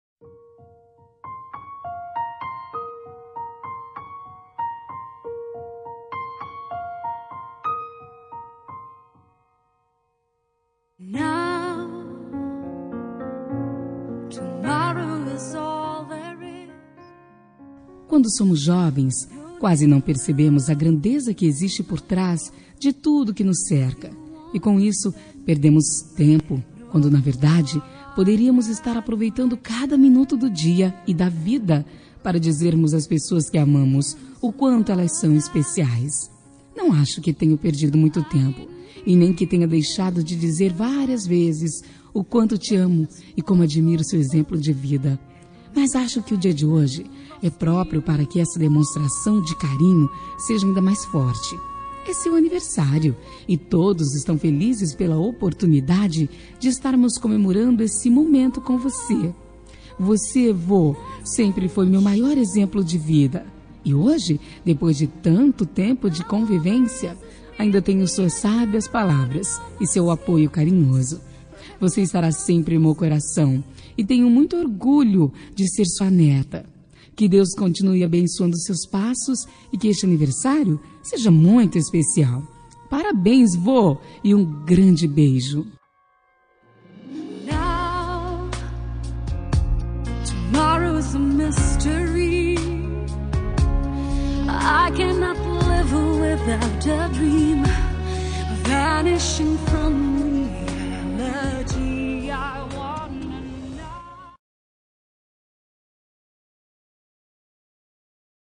Aniversário de Avô – Voz Feminina – Cód: 2084